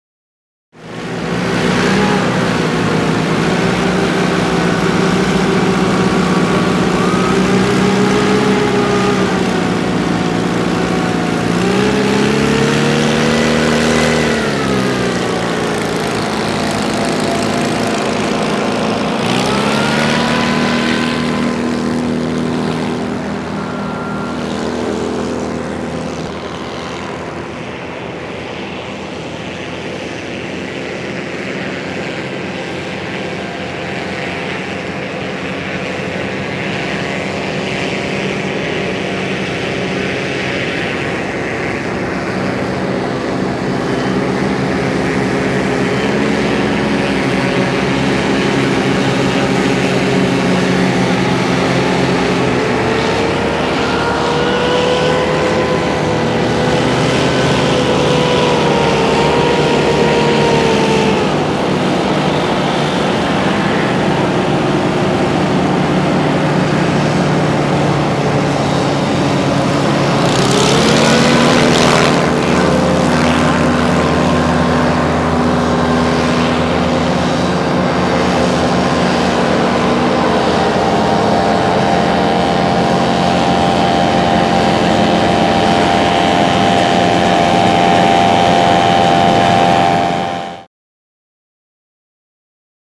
Mini Hovercrafts; Racing; Mini Hovercraft Race. General Buzzing Around By Several Vehicles On Water.